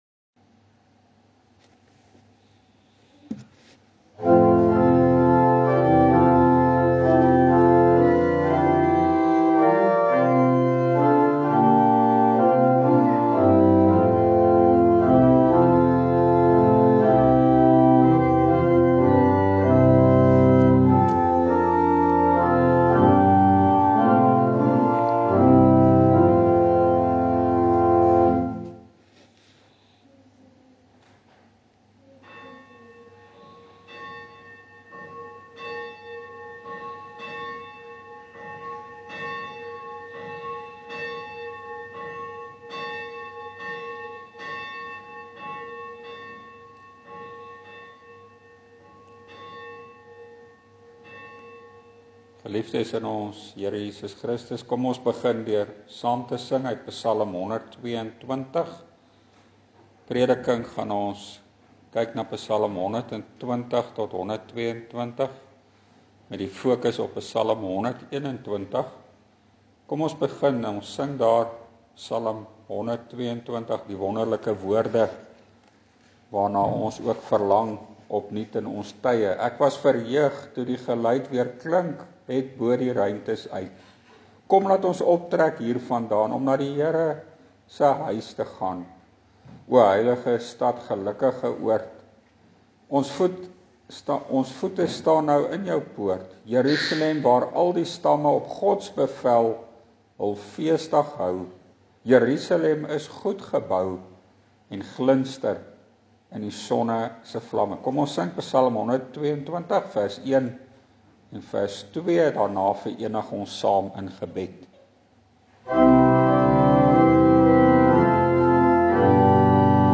PREEK EN BESINNING: Psalms 120-122